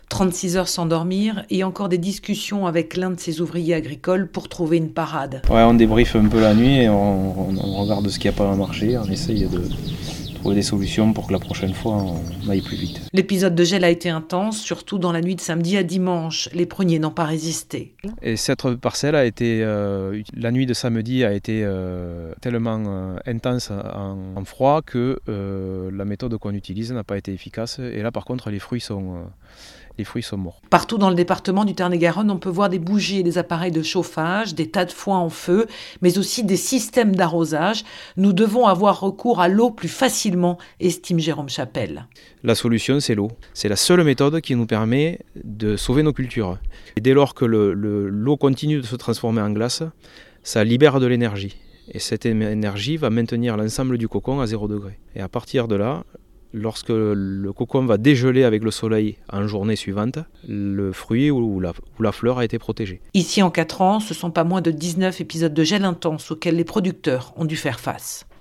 Le gel a fait des ravages dans les vergers du Tarn-et-Garonne. Reportage à Cazes-Mondenard